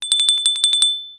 ZVONEK.mp3